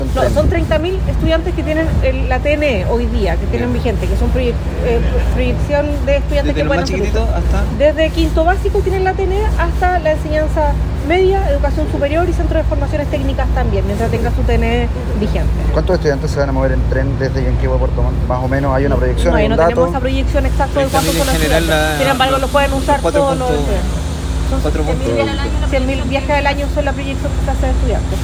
Así lo dijo la directora nacional de Junaeb, Camila Rubio.